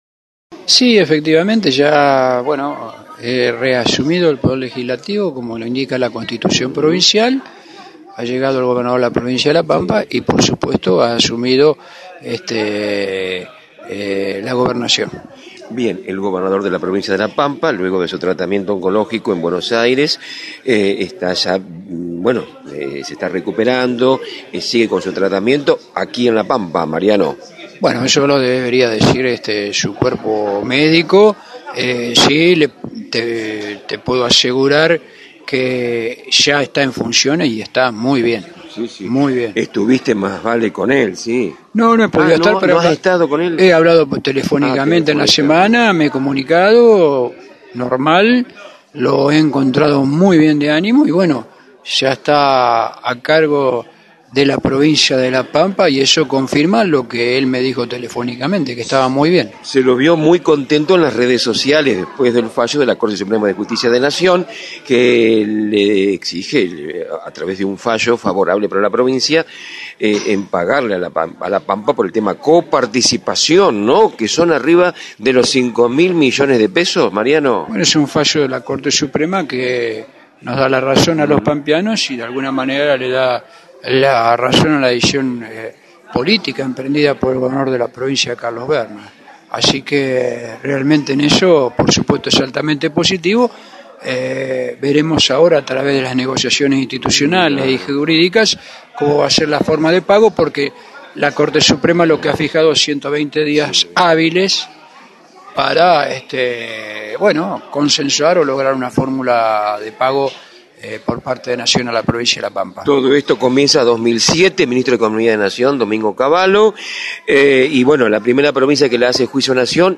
Sobre el mediodía de hoy, el vice gobernador Mariano Fernández, confirmó que Carlos Verna ya se encuentra nuevamente a cargo de la provincia de La Pampa.